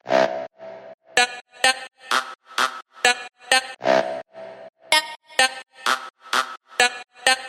墨尔本反弹2合成器和人声引子第二部分
描述：合成器和人声取自一个样本包，我用directwave制作。
标签： 128 bpm Electronic Loops Synth Loops 1.26 MB wav Key : Unknown
声道立体声